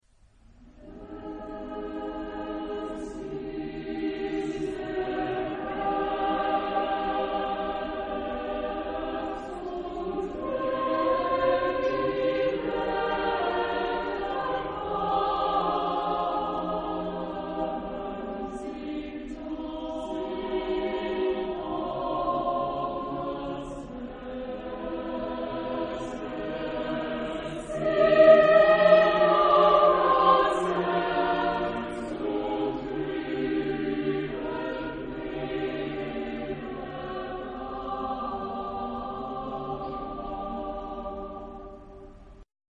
SATB (4 voices mixed).
Romantic. Lied.